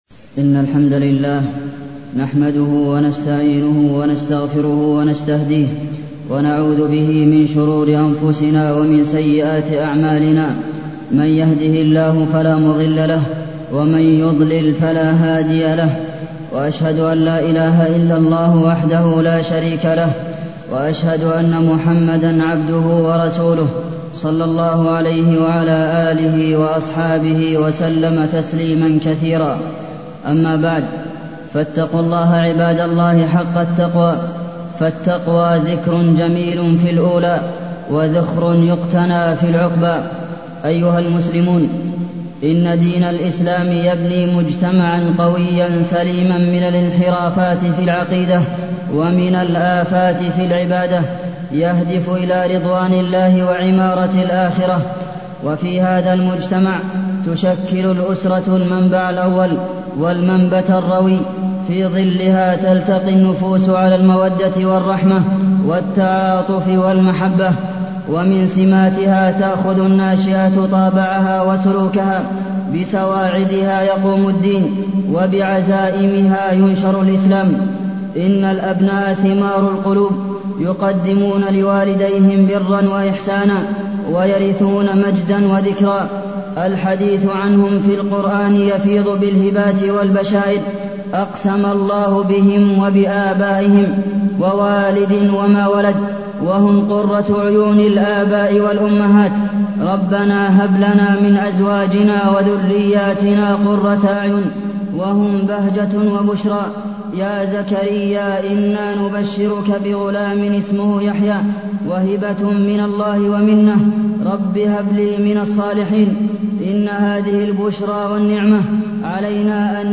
تاريخ النشر ٩ رجب ١٤٢١ هـ المكان: المسجد النبوي الشيخ: فضيلة الشيخ د. عبدالمحسن بن محمد القاسم فضيلة الشيخ د. عبدالمحسن بن محمد القاسم نعمة الأولاد وحسن تربيتهم